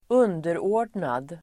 Uttal: [²'un:derå:r_dnad]